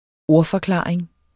Udtale [ ˈoɐ̯- ]